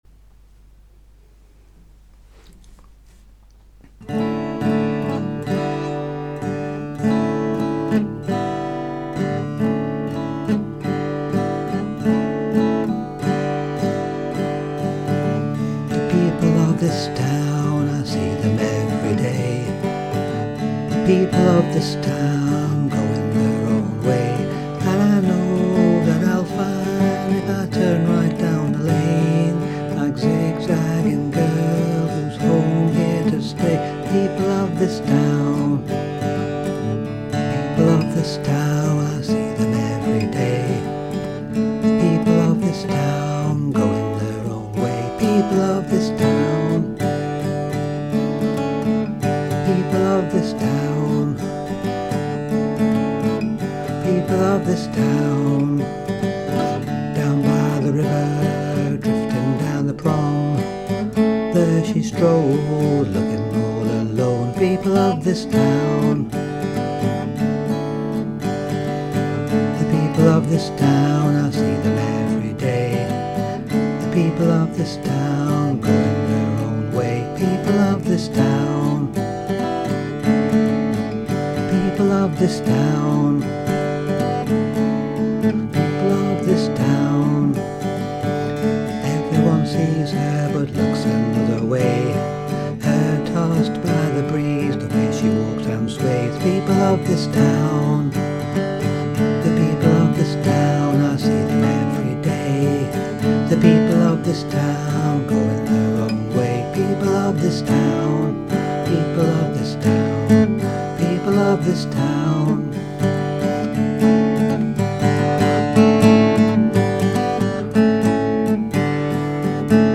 An Indie Demo Single